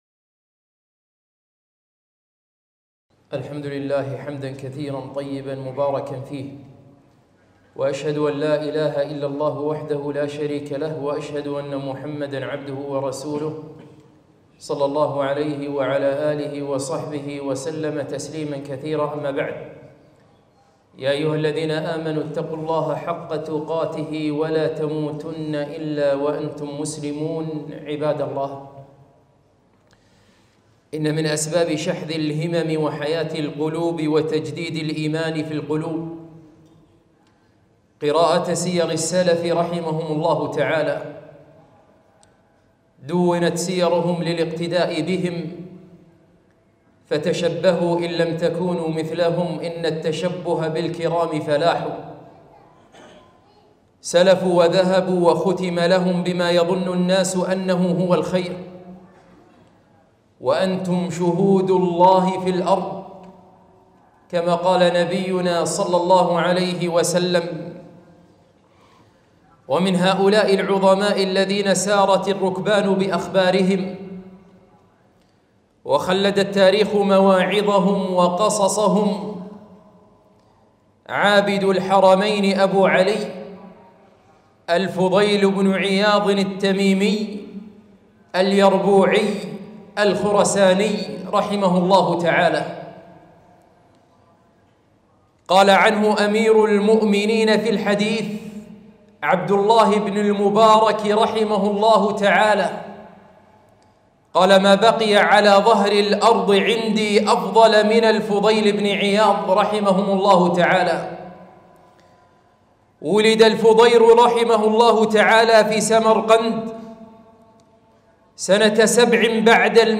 خطبة - من سير السلف عابد الحرمين الفضيل بن عياض